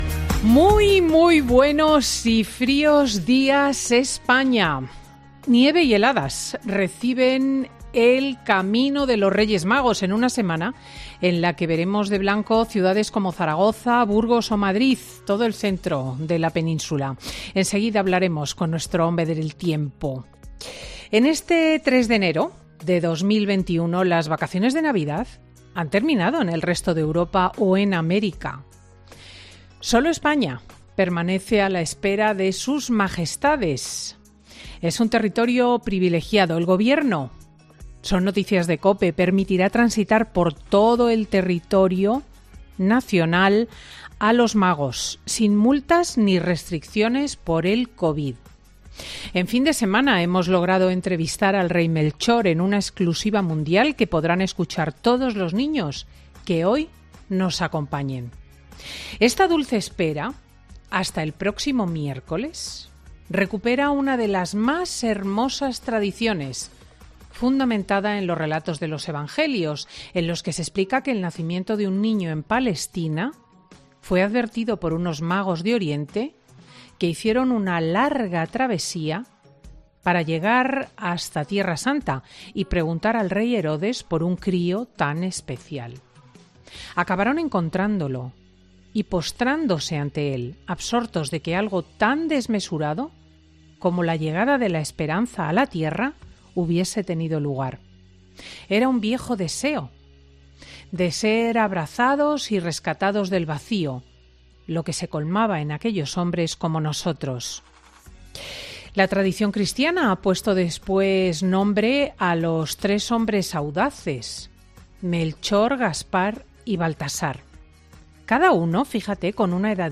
ESCUCHA EL EDITORIAL COMPLETO DE CRISTINA L. SCHLICHTING EN 'FIN DE SEMANA'